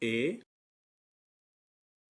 La letra E tiene siempre el mismo sonido y equivale en francés al sonido: " é ".